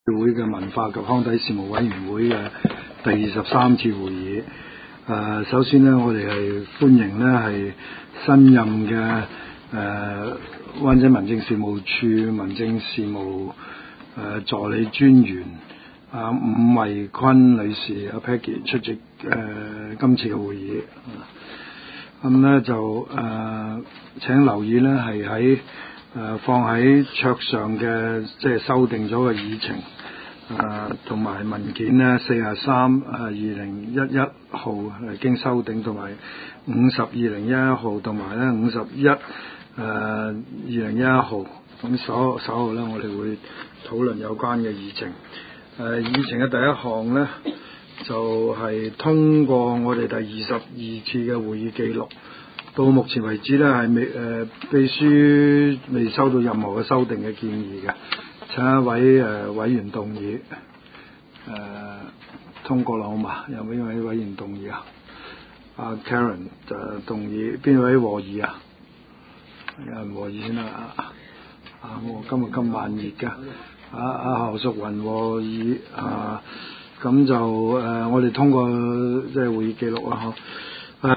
文化及康體事務委員會第二十三次會議
灣仔民政事務處區議會會議室